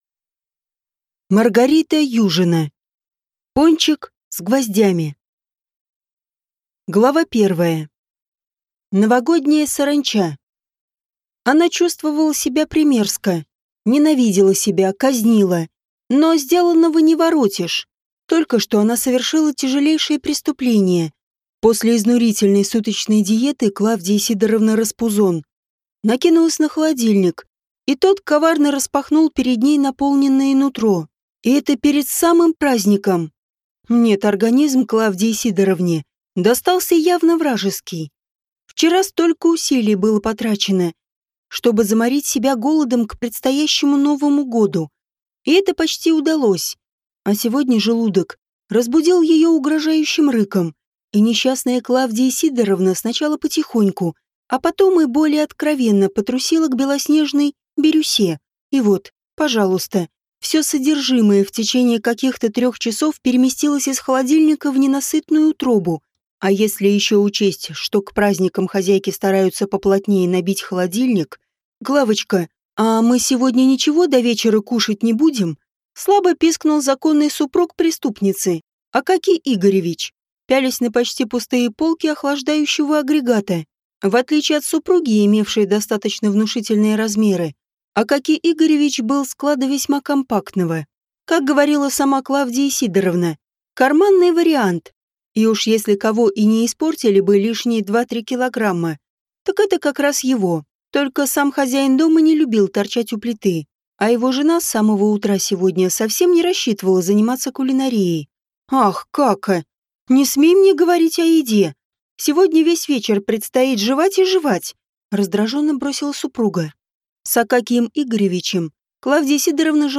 Аудиокнига Пончик с гвоздями | Библиотека аудиокниг